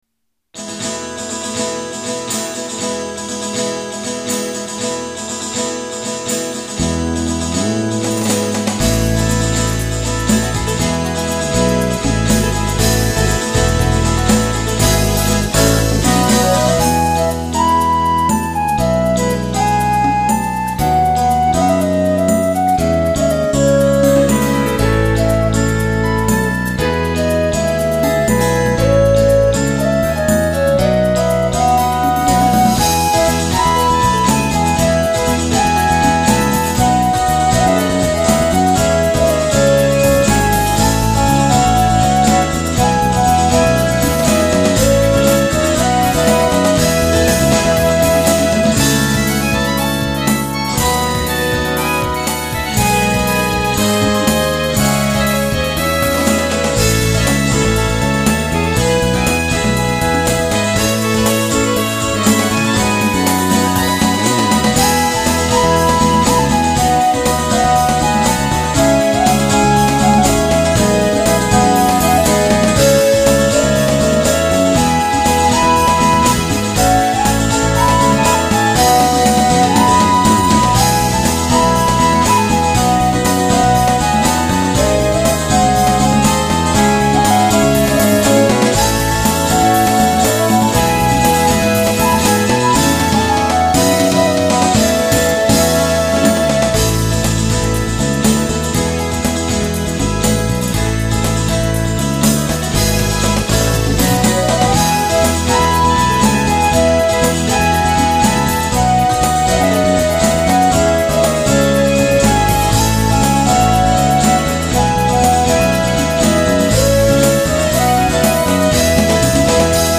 Healing
あえてアコースティックに拘った一曲。
ギター2本とピアノに加え、リズムパートのパーカッション等のサウンドを 多く取り入れた作品です。
リバーブ系エフェクトを多用することで、音に広がりを持たせています。